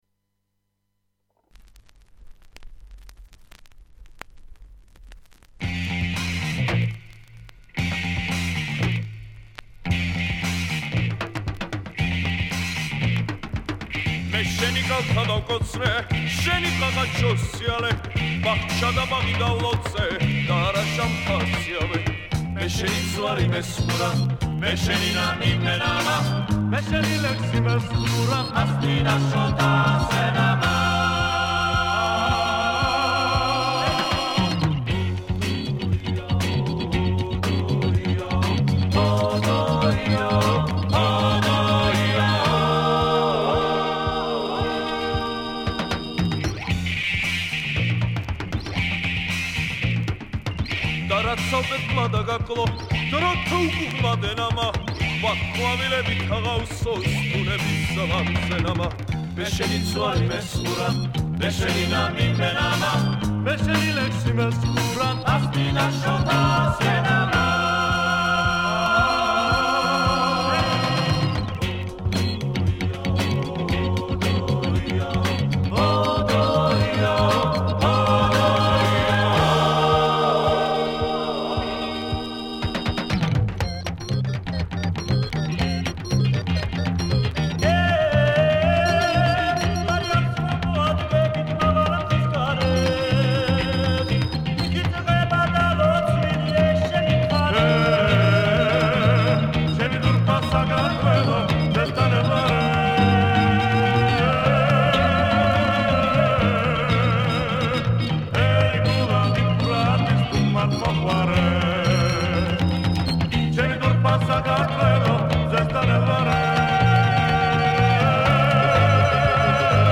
Keywords: ქართული საესტრადო მუსიკა